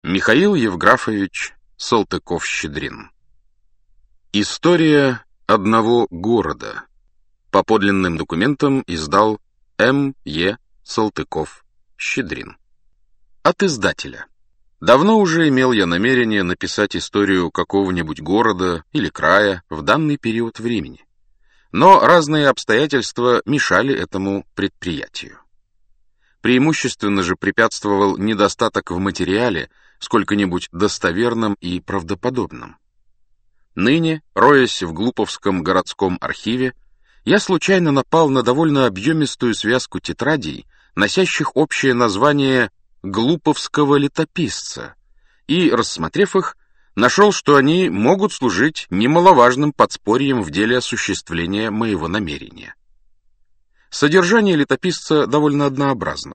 Aудиокнига История одного города Автор Михаил Салтыков-Щедрин Читает аудиокнигу Александр Клюквин.